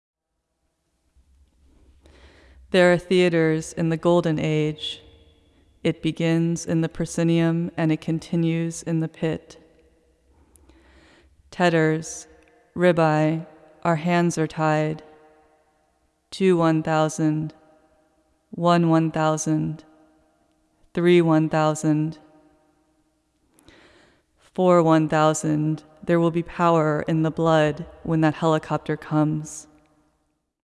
Metal folding chairs are set up inside, for the audience to sit and listen to a pre-recorded oration.